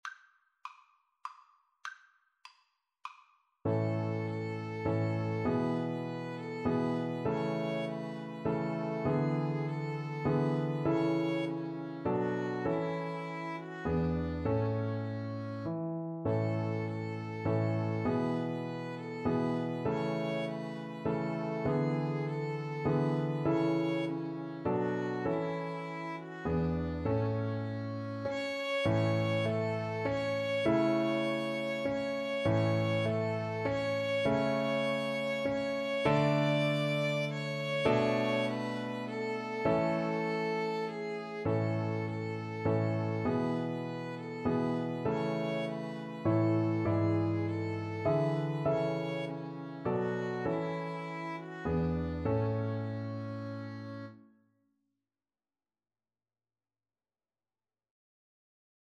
A major (Sounding Pitch) (View more A major Music for Violin Duet )
3/4 (View more 3/4 Music)
Traditional (View more Traditional Violin Duet Music)